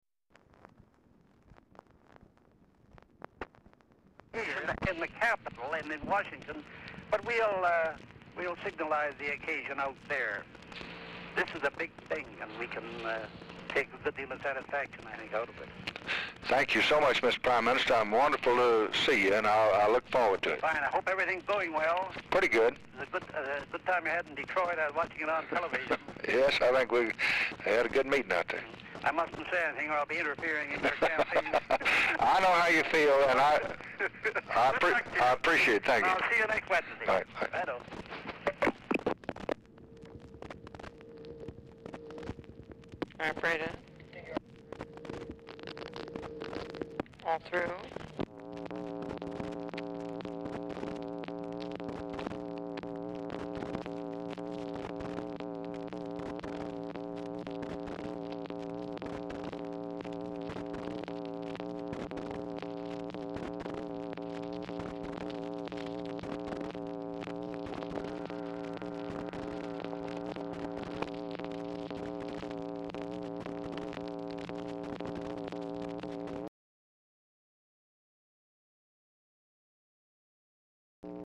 Oval Office or unknown location
"PRIME MINISTER"; "TWO BELTS BECAUSE ONE MACHINE NOT OPERATING CORRECTLY"; RECORDING STARTS AFTER CONVERSATION HAS BEGUN; PARTIAL CONCURRENT RECORDING OF REF #5549
Telephone conversation
Dictation belt